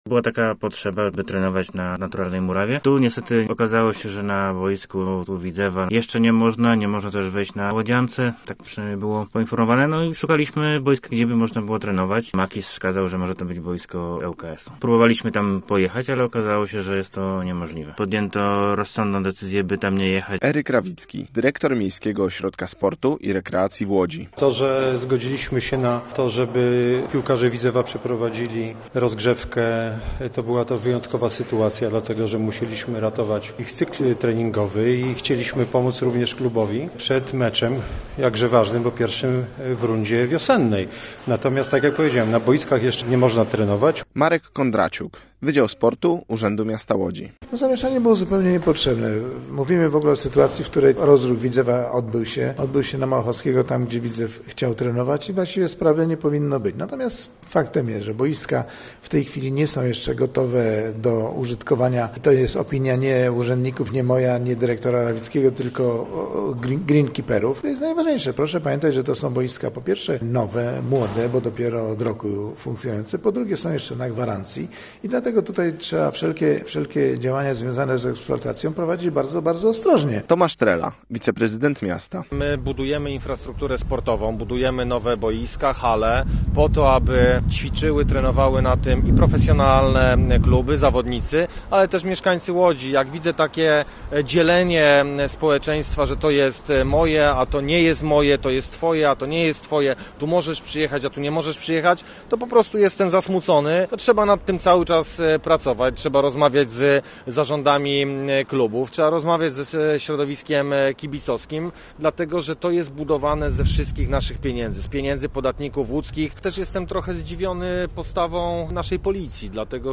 Posłuchaj relacji i dowiedz się więcej: Nazwa Plik Autor Czy trening piłkarzy Widzewa na boisku hybrydowym przy al. Unii był dobrym pomysłem? audio (m4a) audio (oga) Decyzję o tym, że trening ma odbyć się przy al. Unii podjął zarządca tego boiska hybrydowego.